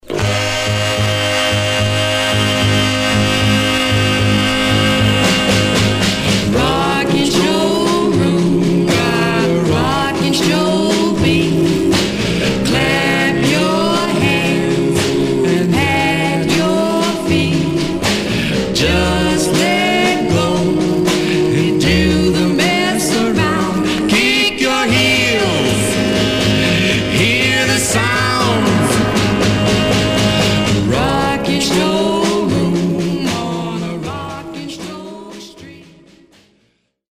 Some surface noise/wear Stereo/mono Mono
45s, Rythm and Blues